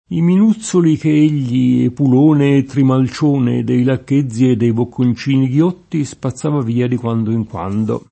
i min2ZZoli ke %l’l’i, epul1ne e ttrimal©1ne dei lakk%zzi e ddei bokkon©&ni gL1tti, SpaZZ#va v&a di kU#ndo ij kU#ndo] (Carducci)